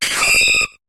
Cri de Mimigal dans Pokémon HOME.